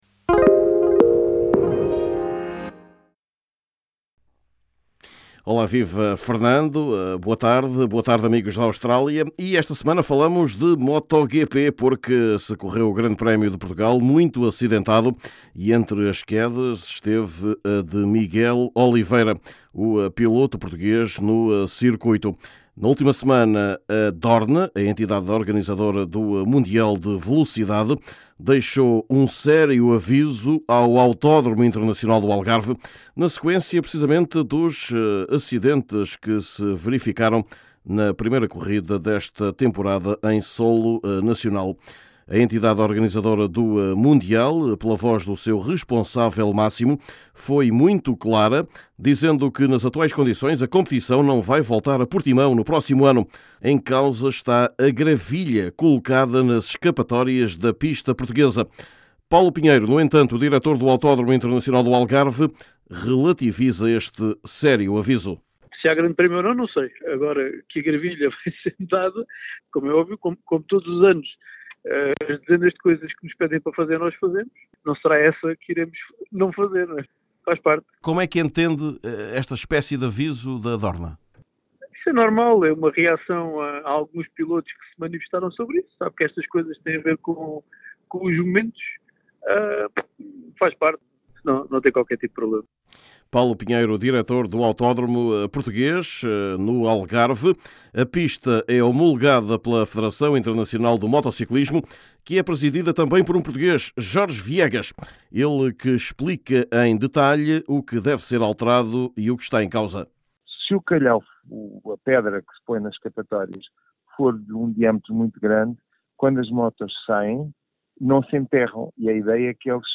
Neste boletim semanal, conhecemos ainda as ameaças ao tenista João Sousa, bem como o nome do primeiro apurado nacional para os Jogos Olímpicos de Paris.